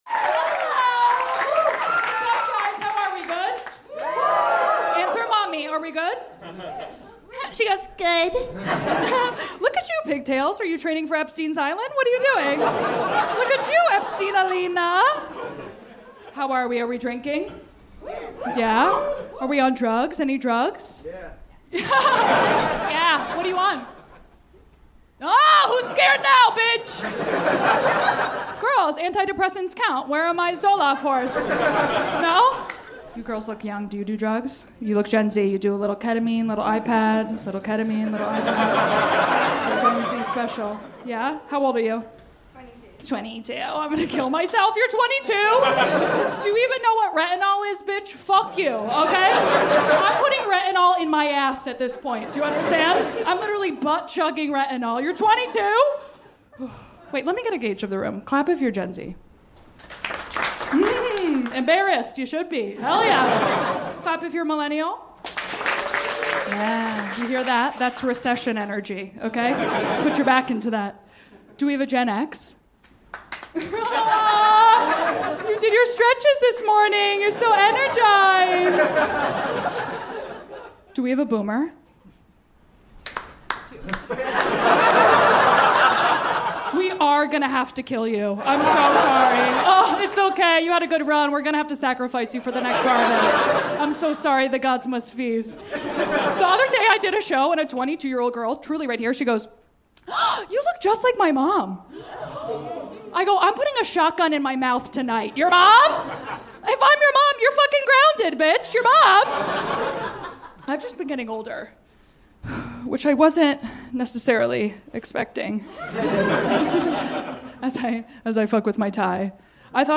The Mommy Manual/ A Stand-Up Guide